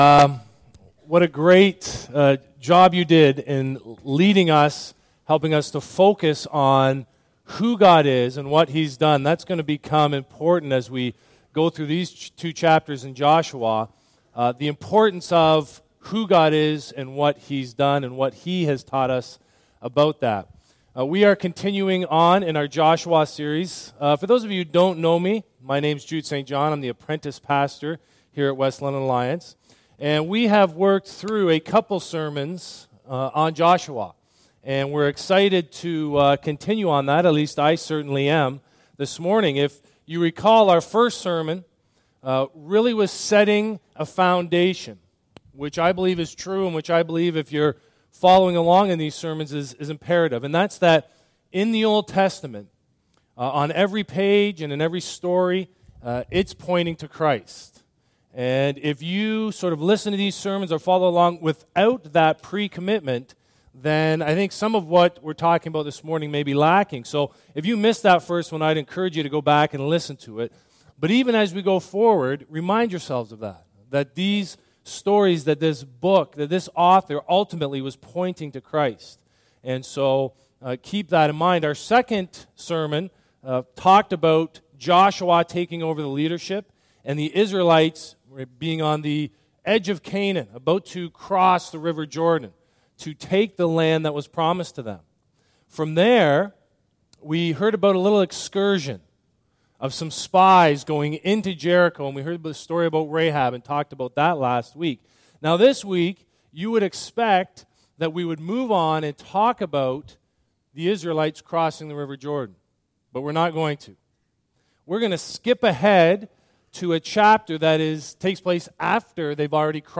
Sermon Archives
In considering these occurrences of renewal, we will have a special focus on how they apply to fathers in this Father's Day sermon.